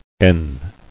[en]